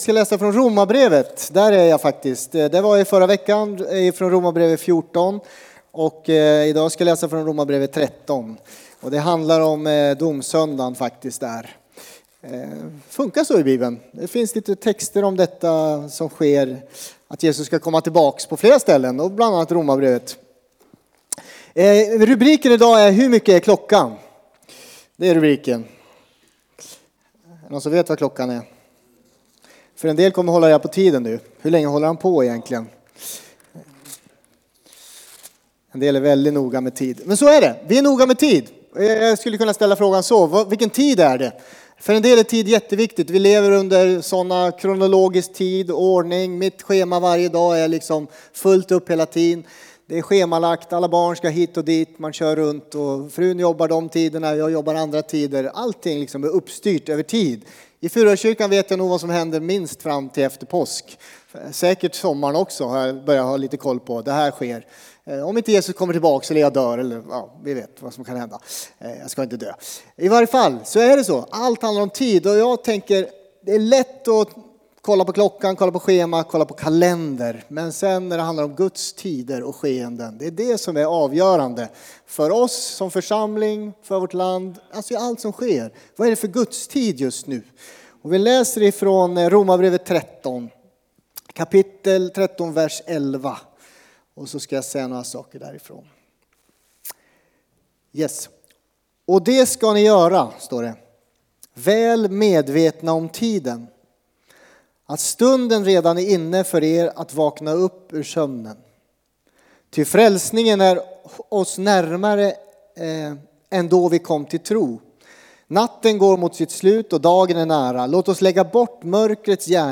Furuhöjdskyrkan, Alunda Predikan